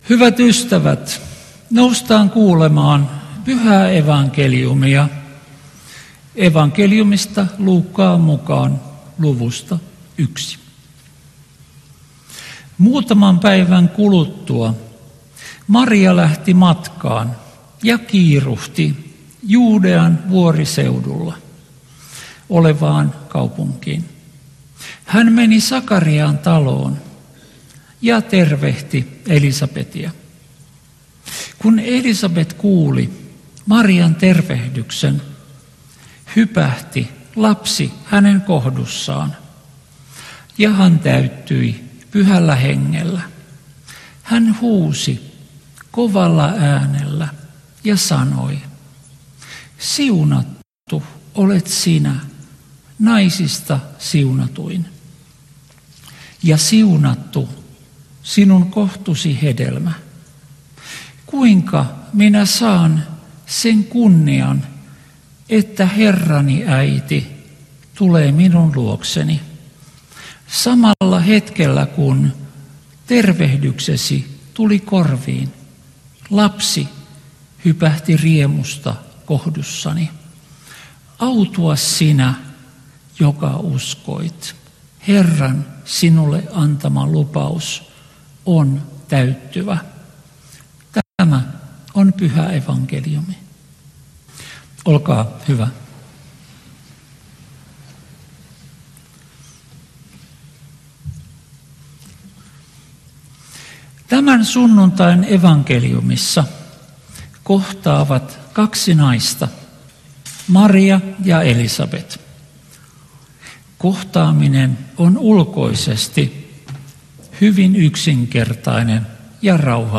Toholammilla Marian ilmestyspäivänä Tekstinä Luuk. 1:39–45